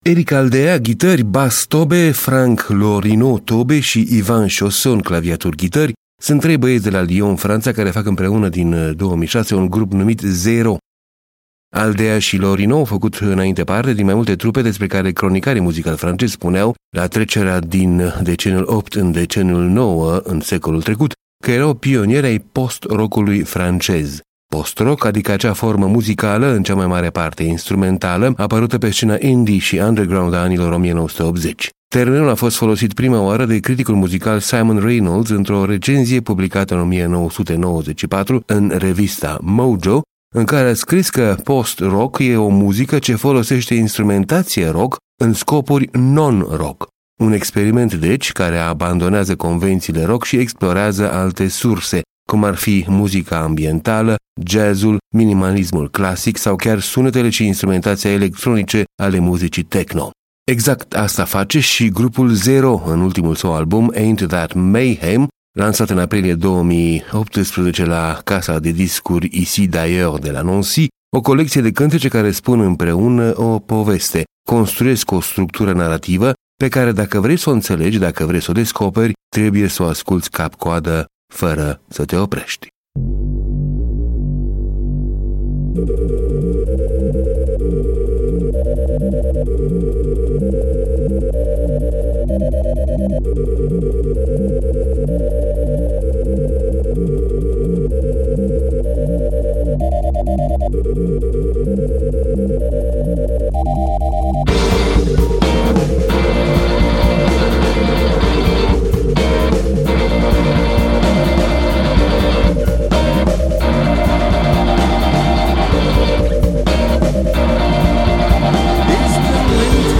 ghitări, bass, tobe
claviaturi, ghitări